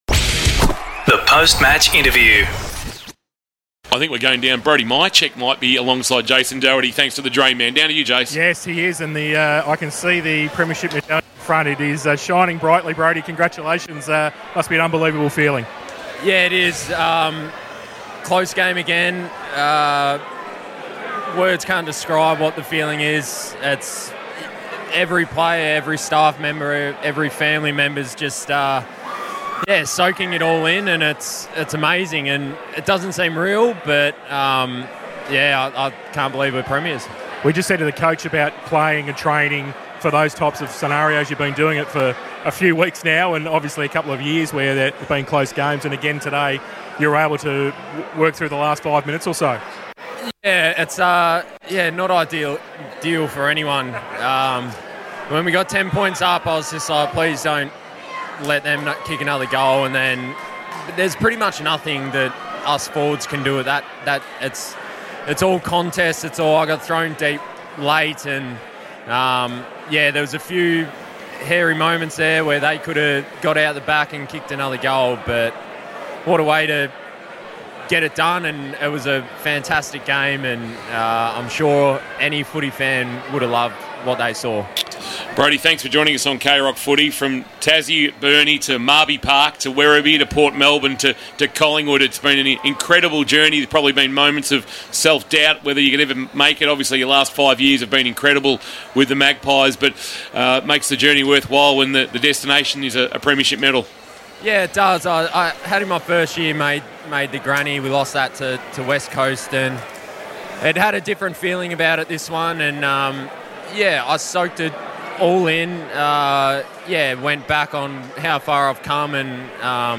2023 - AFL - GRAND FINAL - COLLINGWOOD vs. BRISBANE: Post-match interview - Brody Mihocek (Collingwood)